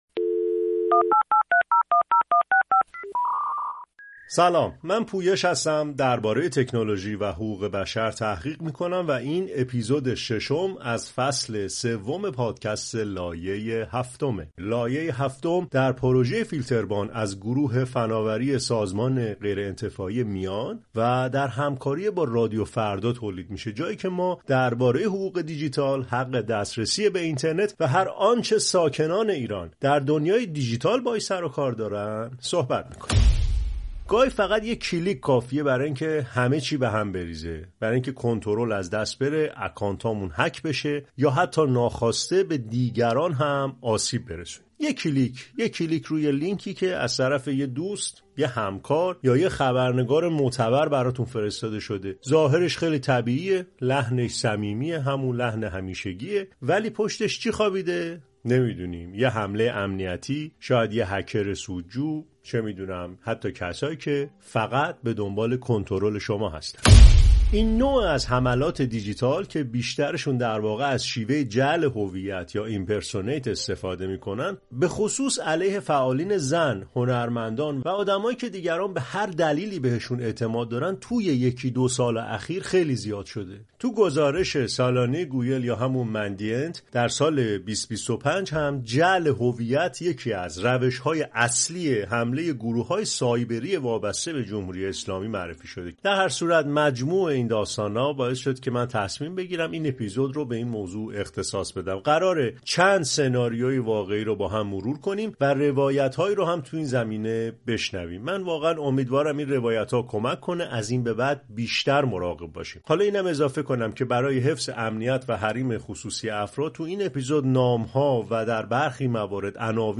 در این قسمت از پادکست «لایه هفتم»، به سراغ حملات سایبری جدیدی می‌رویم که در آن مهاجمان با جعل هویت خبرنگاران، فعالان اجتماعی و حتی سازمان‌های معتبر بین‌المللی، فعالان زن و اعضای جامعه هنری را هدف قرار داده‌اند. در این اپیزود چند سناریوی واقعی از این حملات را با جزئیات و به زبان خود قربانیان مرور می‌کنیم تا نشان دهیم چگونه اعتماد و ارتباطات روزمره می‌توانند به ابزاری برای نفوذ و سوءاستفاده تبدیل شوند. در پایان هم راهکارهایی ساده ولی مؤثر برای حفاظت از امنیت دیجیتال ارائه می‌دهیم.